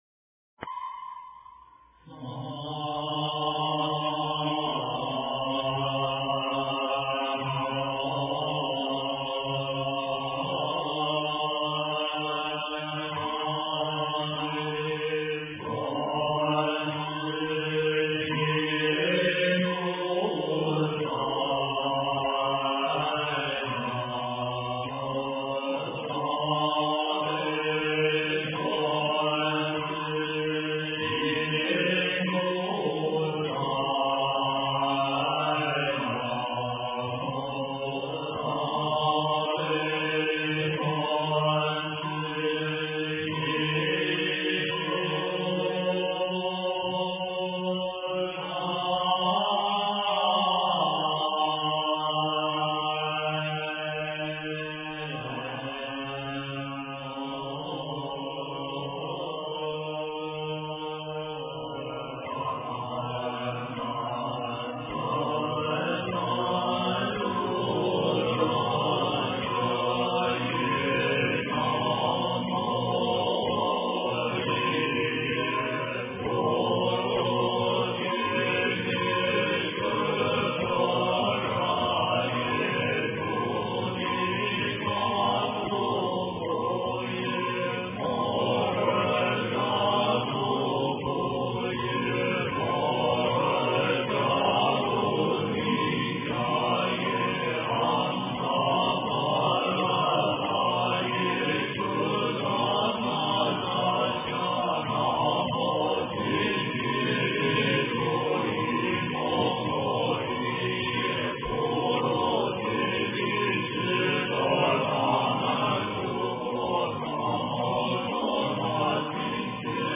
诵经
佛音 诵经 佛教音乐 返回列表 上一篇： 心经 下一篇： 大悲咒 相关文章 般若佛母心咒 般若佛母心咒--群星...